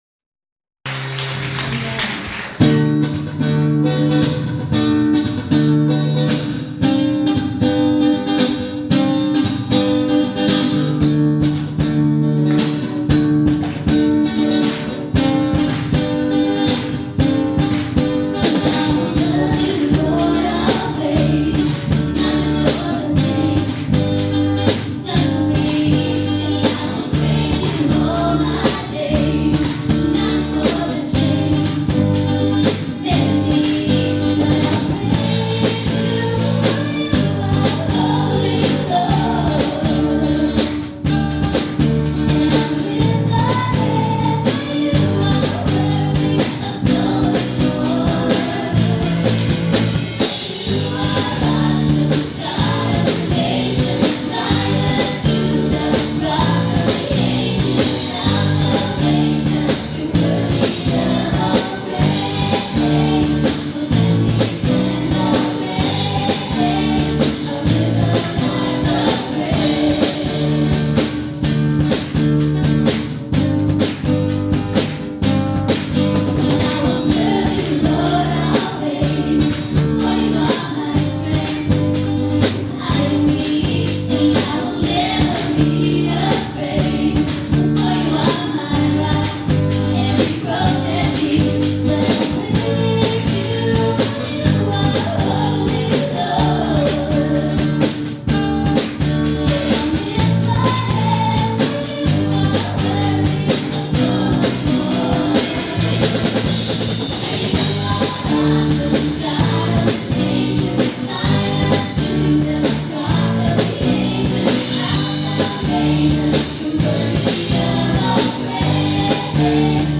PBC WORSHIP TEAM LIVE!
PERCUSSION
DRUMS